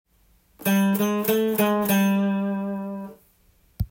①のフレーズ同様コードトーンに向かって弾いていますが
度数が５度になっています。